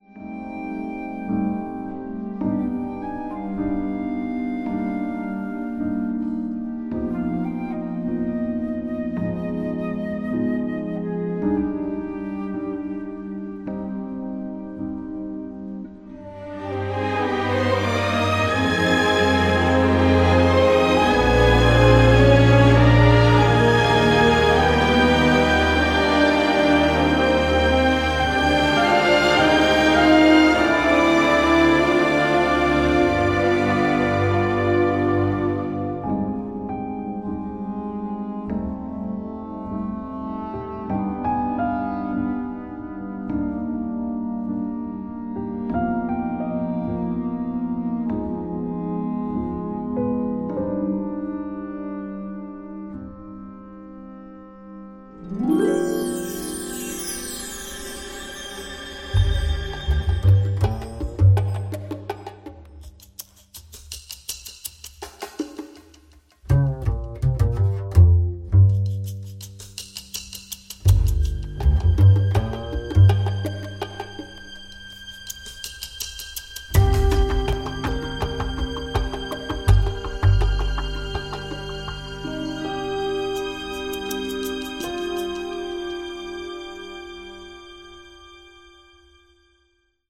propulsive orchestral score
drenched in delightful old school film-noir vibes